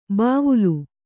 ♪ bāvulu